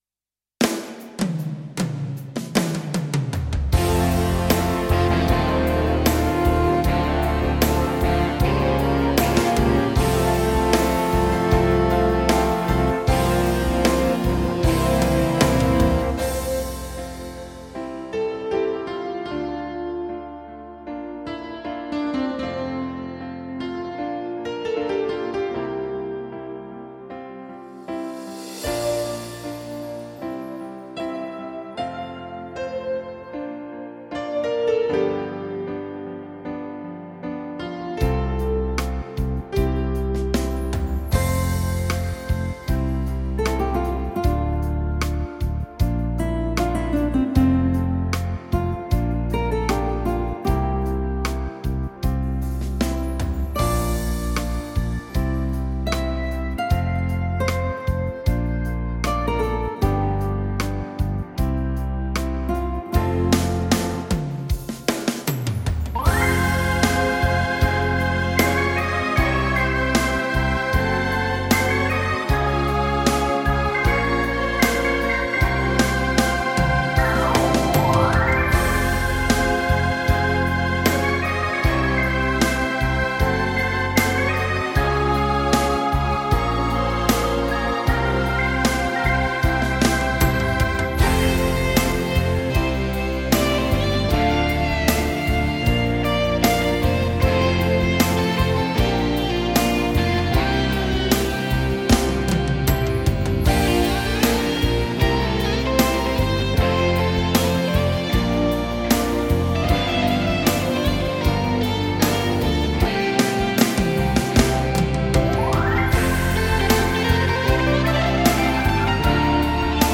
Alors : PSRS 770    Style léger mixing Tempo 68
StageLead&VintageSprings - MidnightDX&BalladDX
Multipiste, mixing, effets etc .....